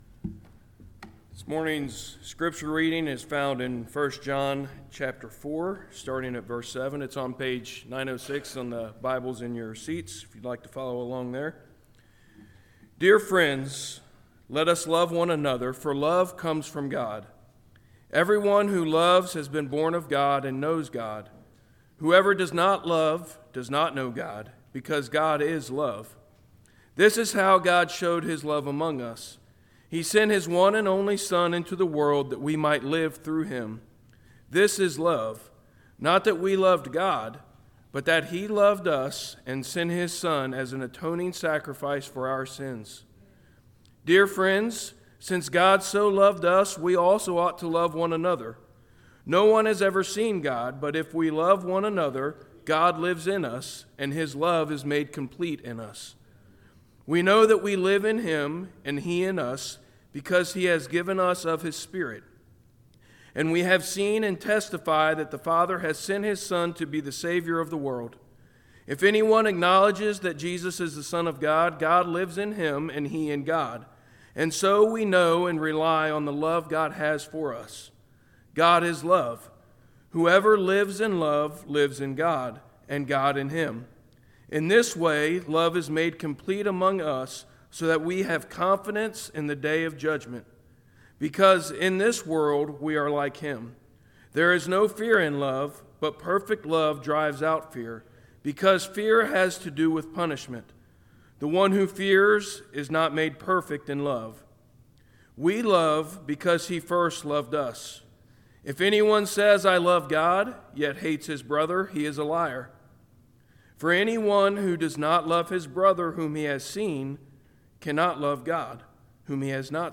Sermons | Florence Alliance Church